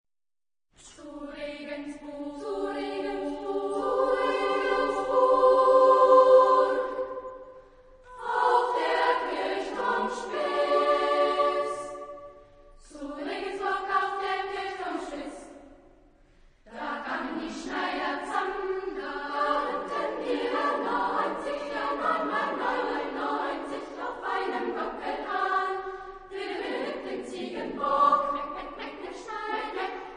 Genre-Style-Form: Folk music ; Partsong ; Secular
Type of Choir: SSAA  (4 women voices )
Tonality: G major
Discographic ref. : 4.Deutscher Chorwettbewerb, 1994